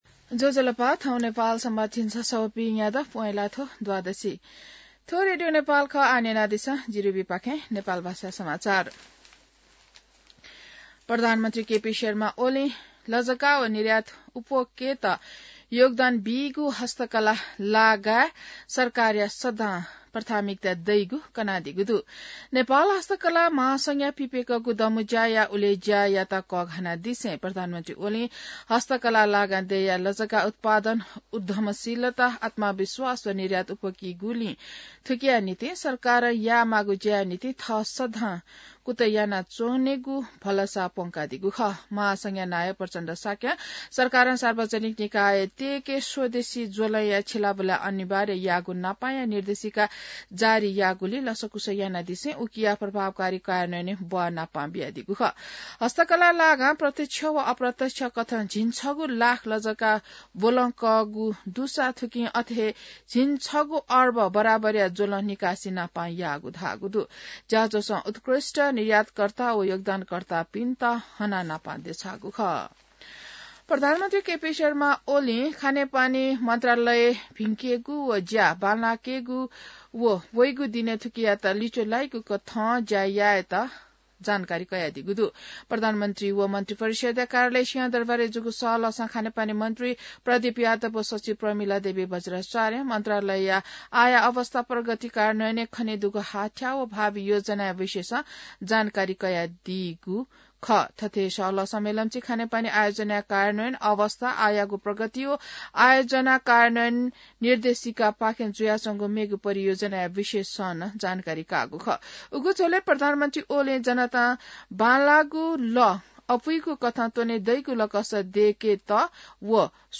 नेपाल भाषामा समाचार : २८ पुष , २०८१